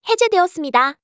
audio_disengage.wav